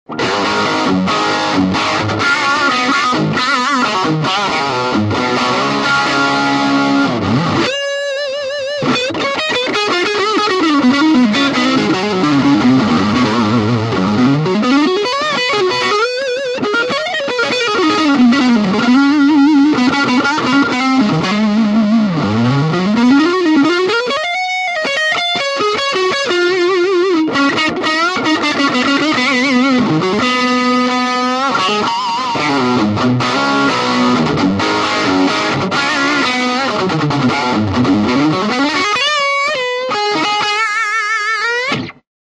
Two different Distortion Blender - Bold Distortion
This effect has the mixed outputs of bold tone. Both inside Crunch Distortion and Heavy Distortion are mixed half-and-half.
Demo with Humbucker Pickup 1